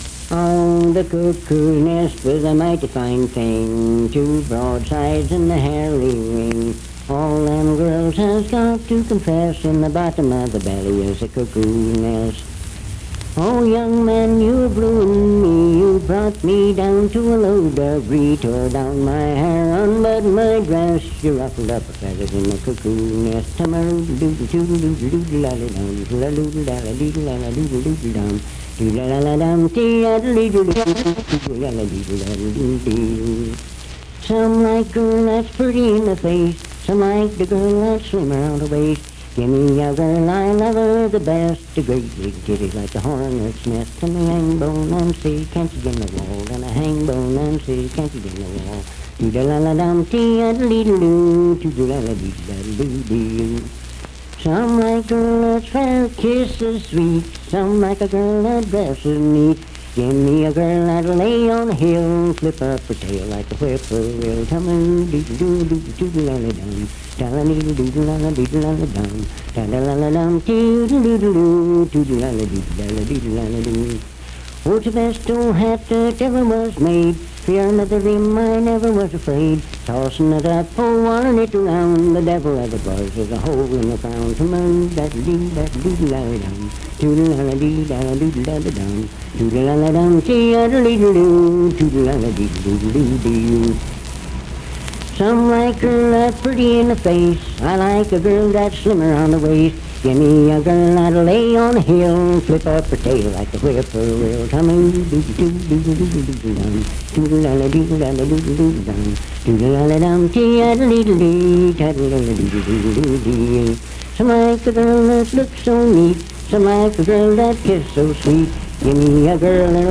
Unaccompanied vocal music
Performed in Sandyville, Jackson County, WV.
Bawdy Songs
Voice (sung)